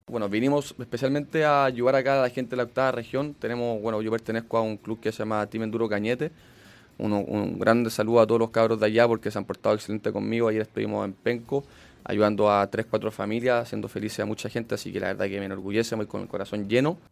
A tres semanas del hecho, Barbosa entregó nuevos detalles en entrevista con Radio Bío Bío en Concepción.